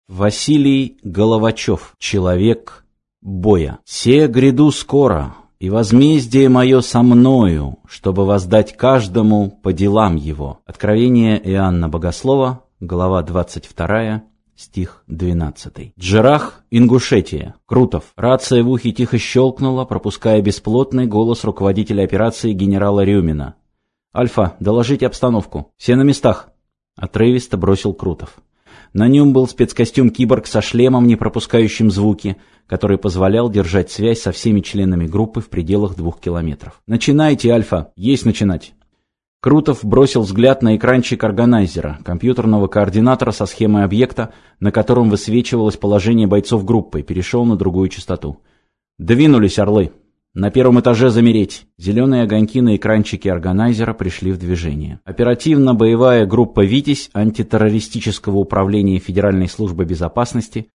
Аудиокнига Человек боя | Библиотека аудиокниг